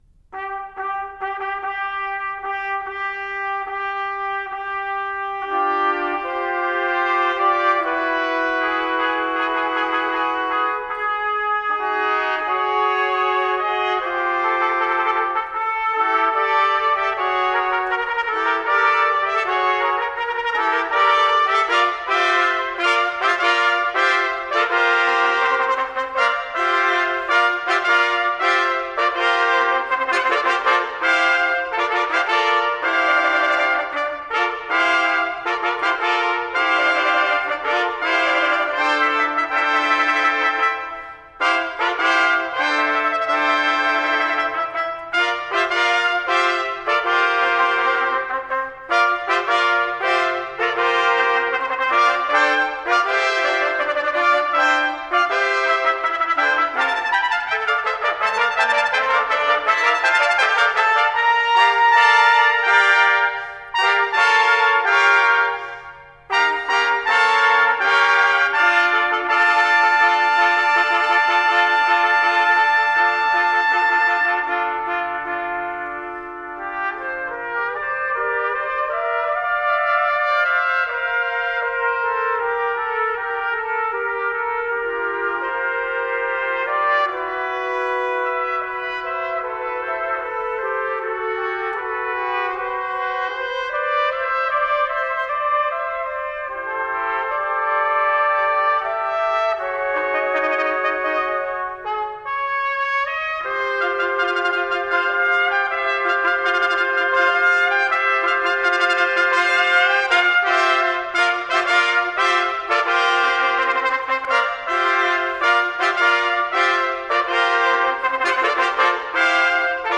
Instrumentation: 6 Trumpets
Category: Fanfare, Chamber Music
Ensemble: Trumpet Choir